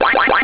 gs_ghostblue.au